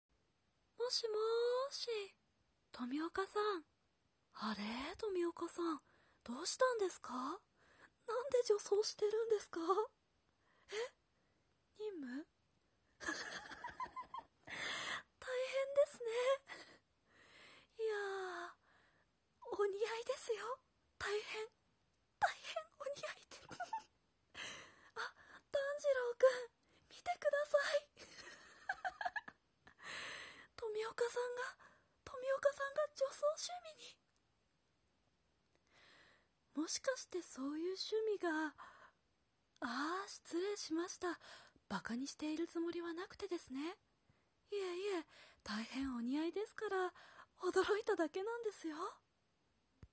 声真似練習